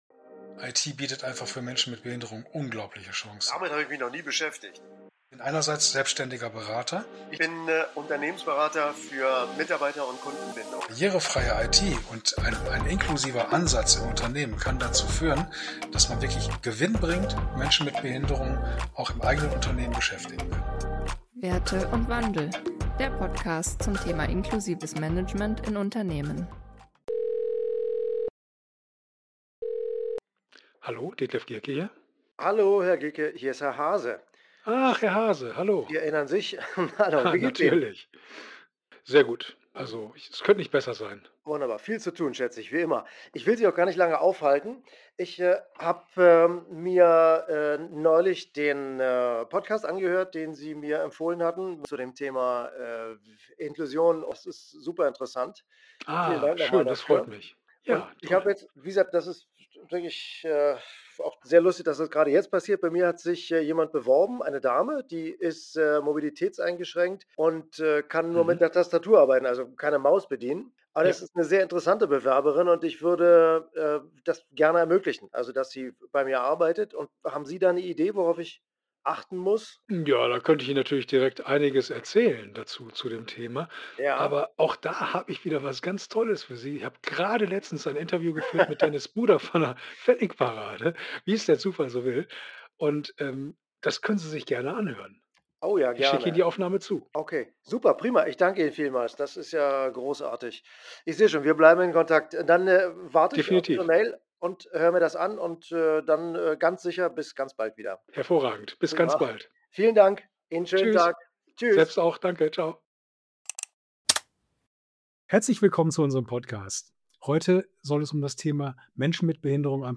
Die beiden Experten teilen ihre Erfahrungen und betonen die Notwendigkeit von Inklusion in Unternehmen sowie die Rolle von Technologie und Hilfsmitteln.